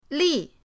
li4.mp3